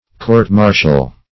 Court-martial \Court`-mar"tial\, v. t. [imp. & p. p.